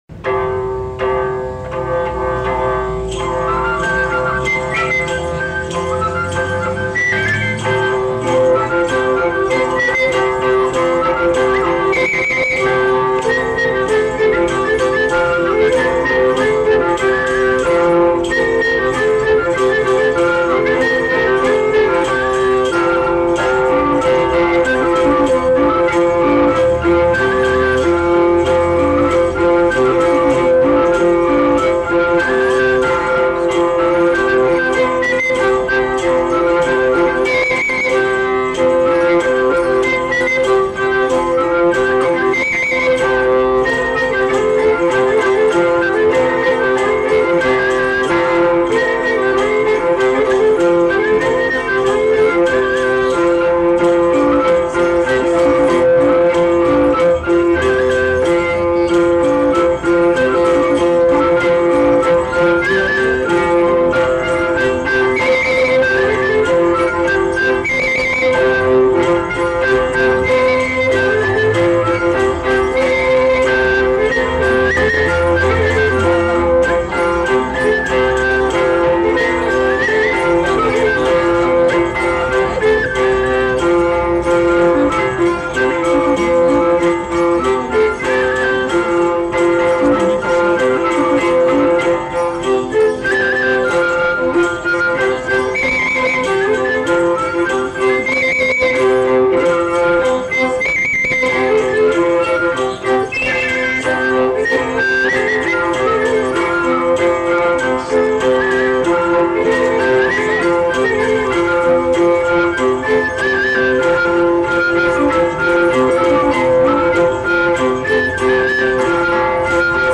Lieu : Pinerolo
Genre : morceau instrumental
Instrument de musique : violon ; flûte à trois trous ; tambourin à cordes
Danse : saut béarnais